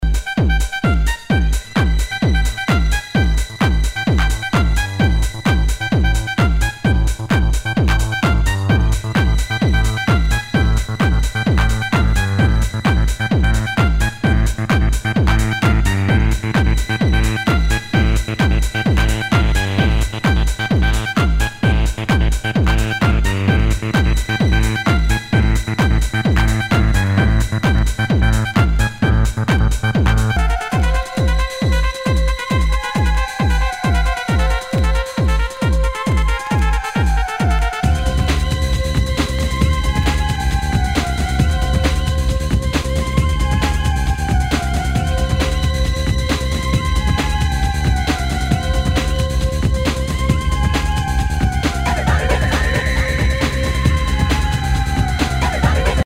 HOUSE/TECHNO/ELECTRO
全体にチリノイズが入ります